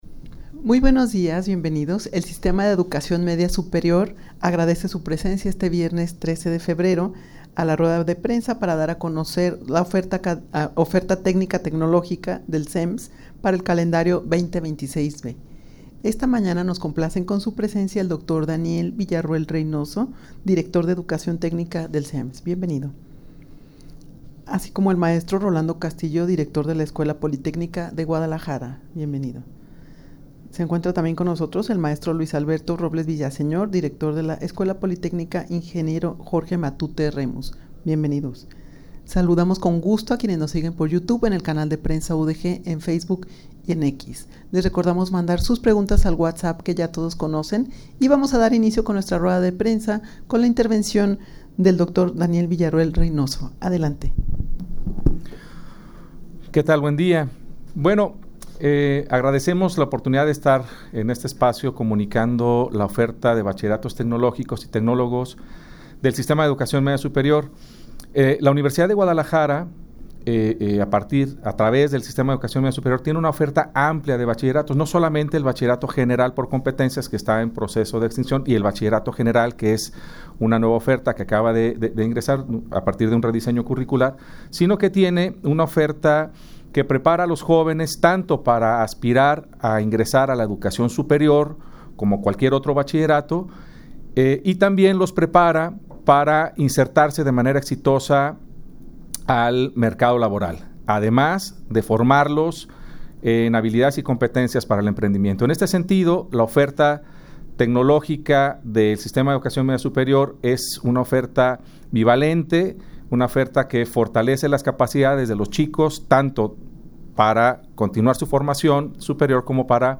Audio de la Rueda de Prensa
rueda-de-prensa-para-dar-a-conocer-su-oferta-tecnica-tecnologica-del-sems-para-el-calendario-2026-b_0.mp3